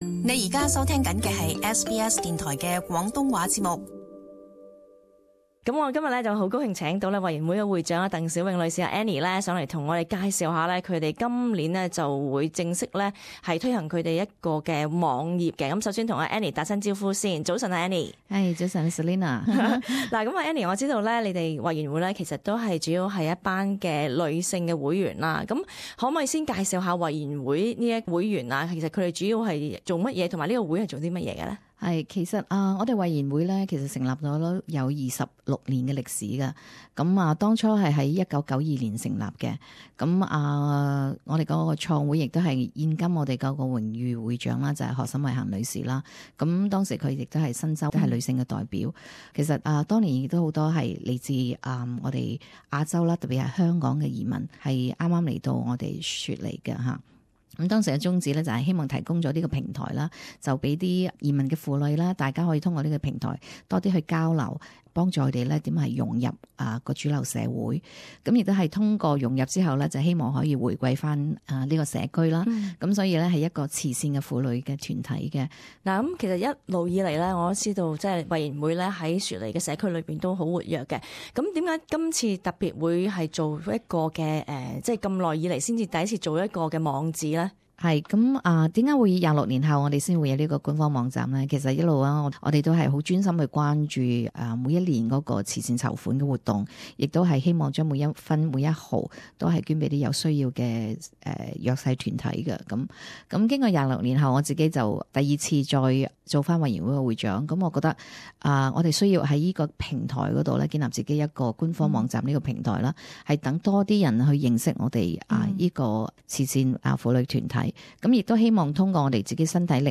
社区专访